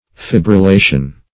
Fibrillation \Fi`bril*la"tion\, n.